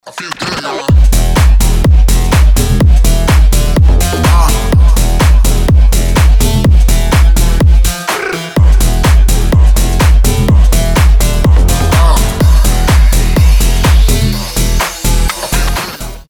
• Качество: 320, Stereo
громкие
жесткие
мощные басы
Bass House
качающие